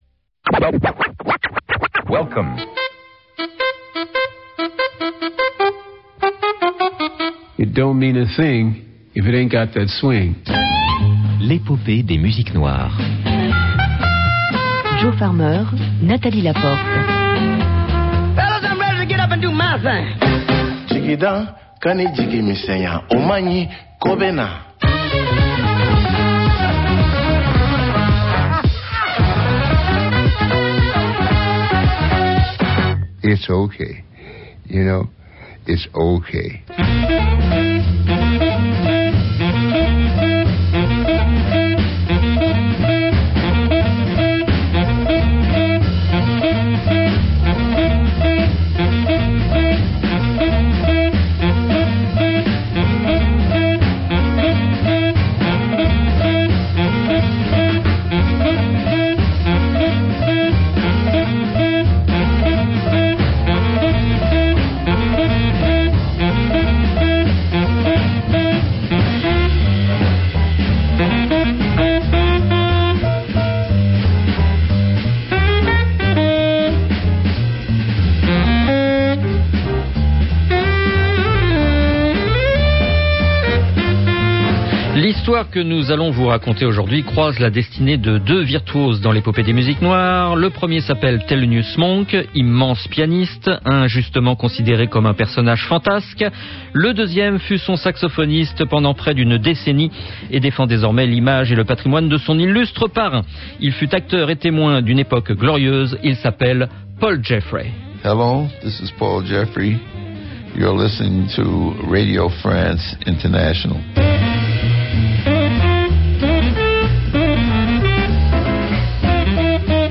Rencontre avec l’un des témoins et acteurs d’une épopée prestigieuse qui nourrit toujours aujourd’hui la vitalité du patrimoine noir.